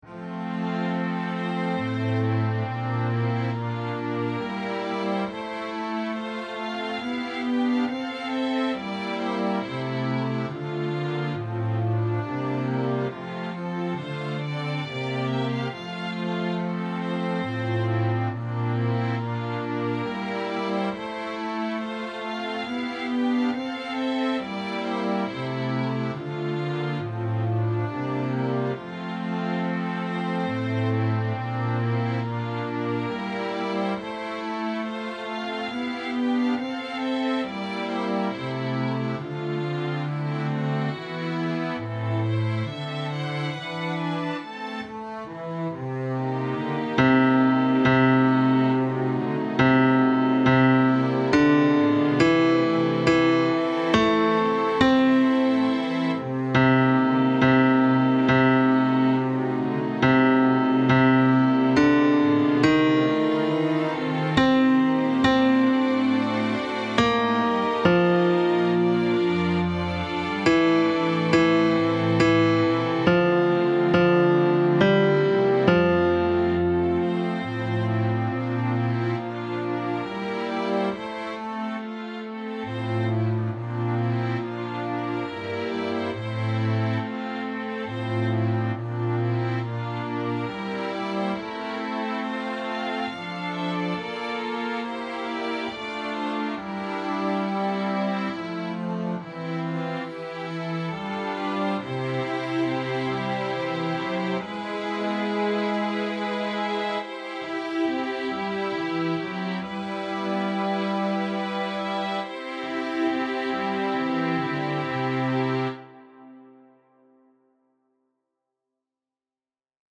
Bajo II
5.-Agnus-Dei-BAJO-II-MUSICA.mp3